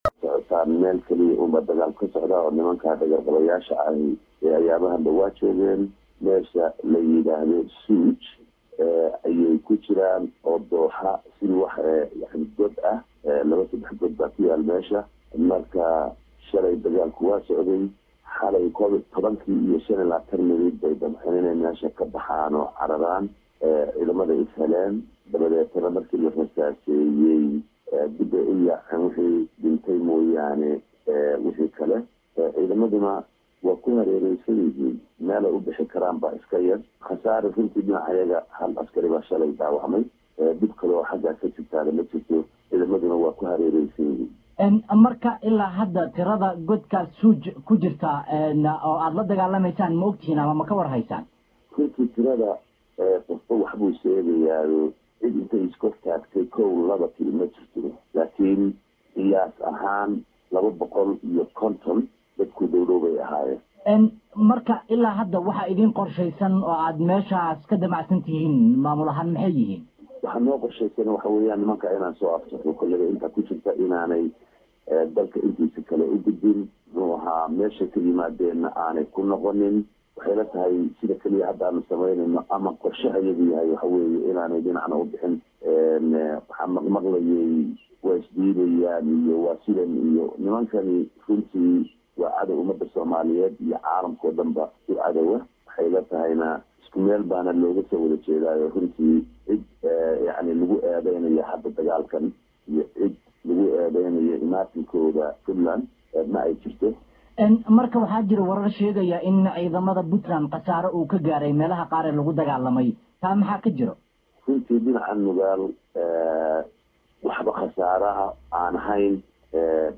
Waraysi: Xalay argagixisadu waxay isku daydey in ay ka baxsato dooxada SUUJ qaybna waan ka dilney: Camey